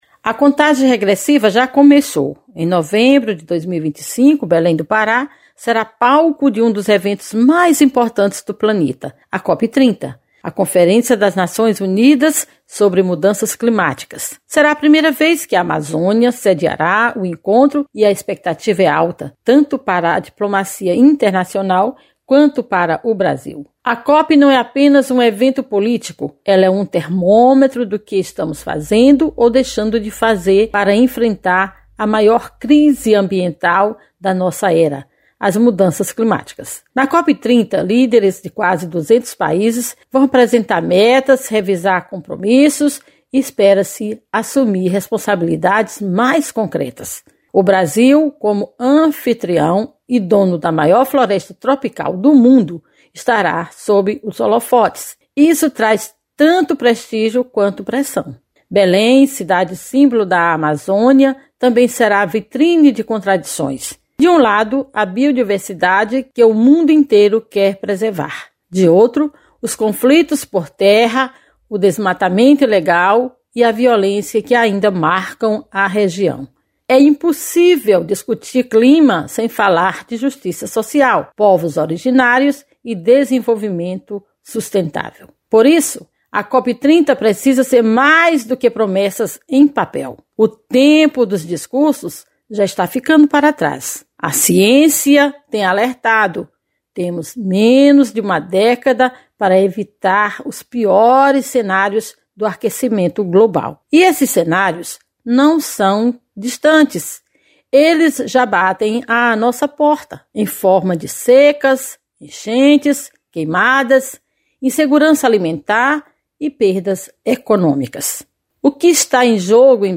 EDITORIAL.mp3